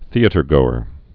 (thēə-tər-gōər)